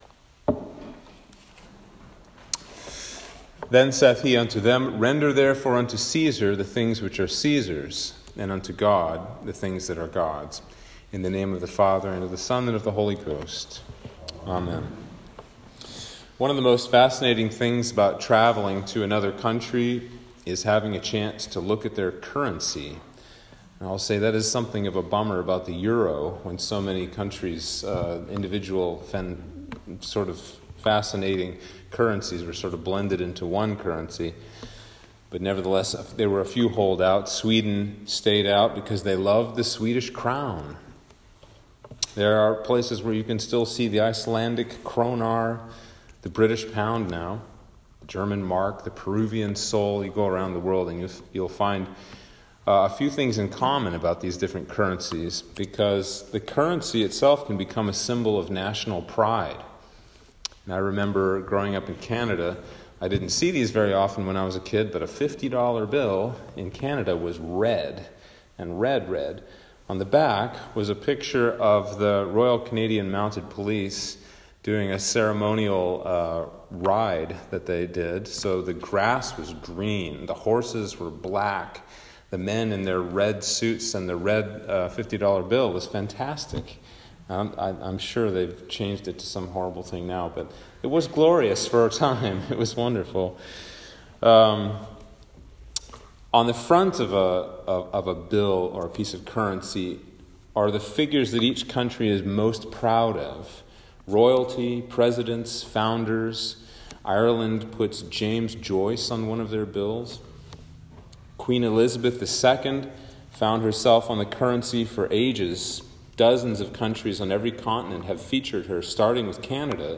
Sermon for Trinity 23 - 2021